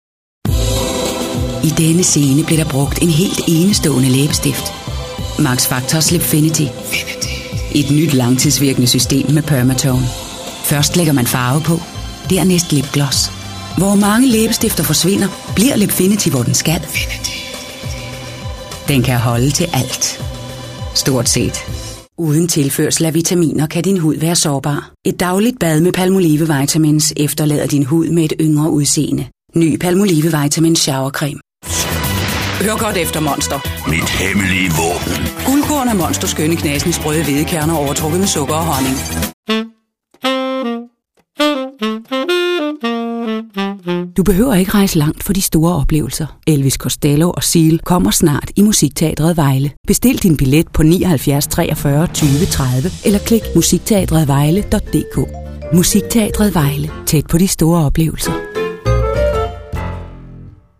Profi Sprecherin dänisch. Natural speech. Warm, Persuasive, Mature, Narration, commercials, telephone systems
Sprechprobe: Werbung (Muttersprache):
Highly experienced professional female danish voice over artist. Natural speech. Warm, Persuasive, Mature, Narration, commercials, telephone systems